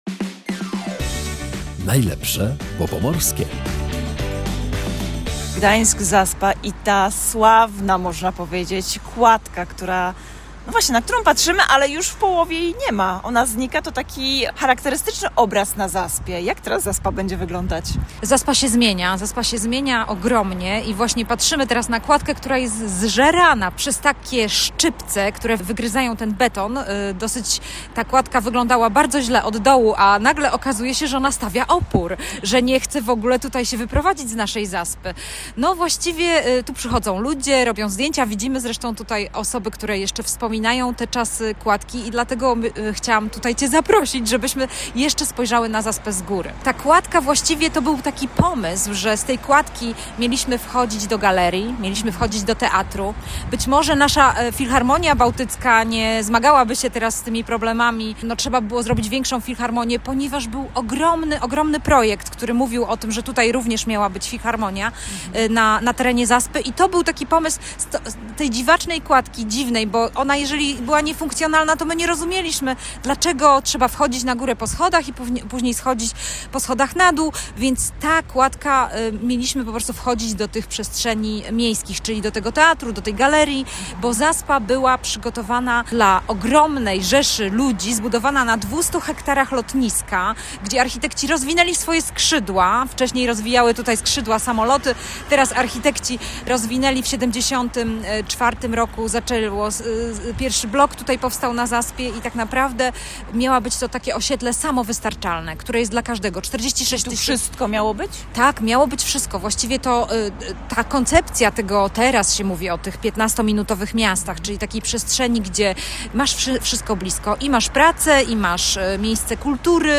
Zapraszamy na spacer po Zaspie